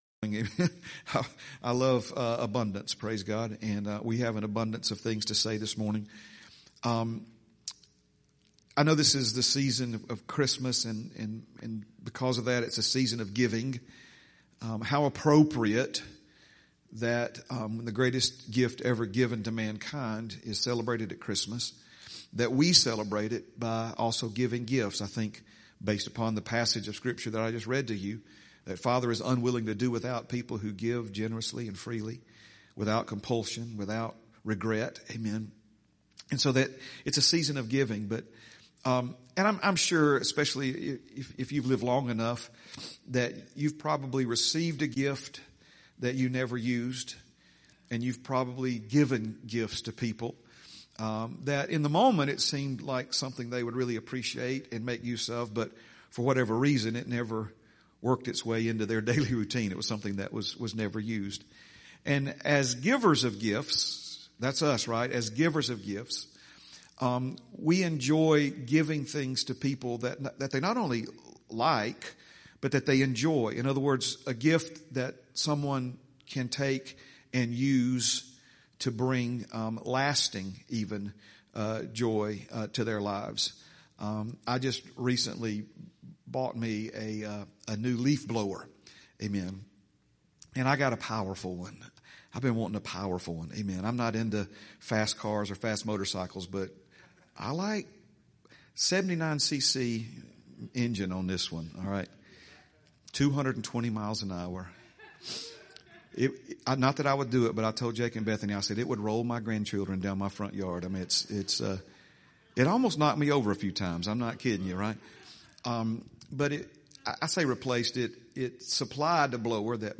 12/14/25 Sunday - Sunday Morning Message